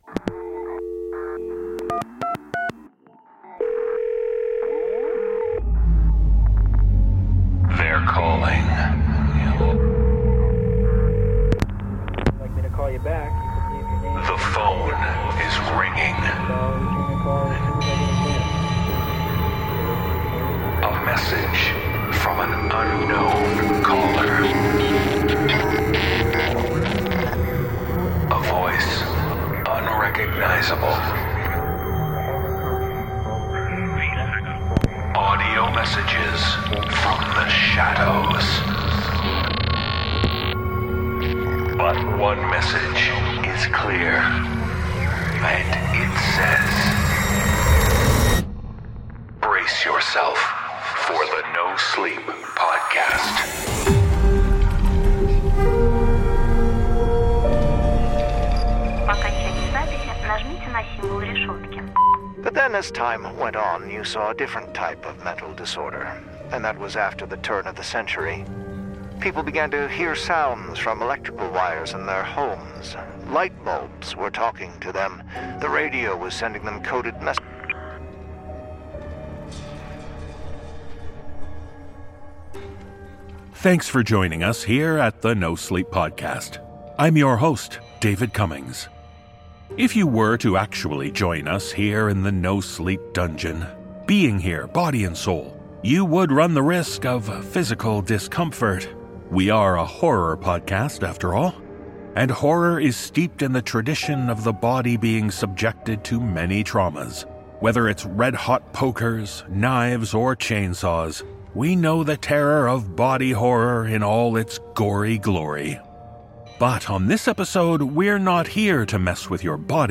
The voices are calling with tales of sinister psyches.